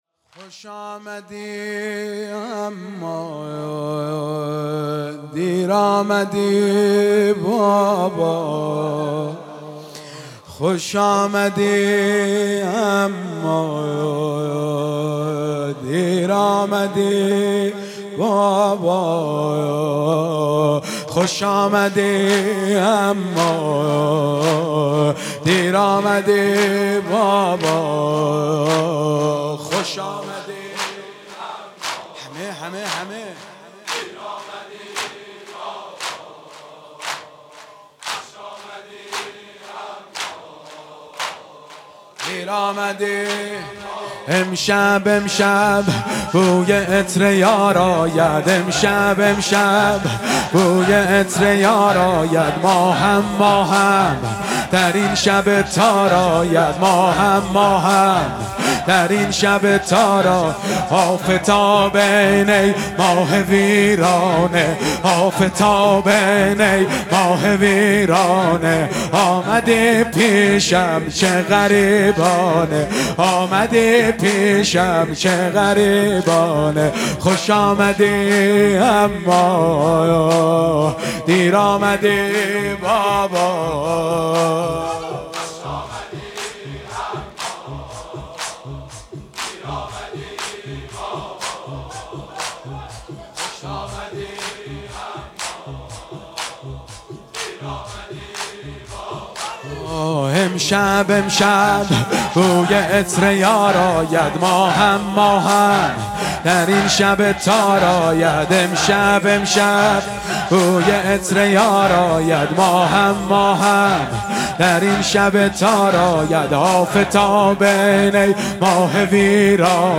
مداحی شب سوم محرم
در هیئت عبدالله بن الحسن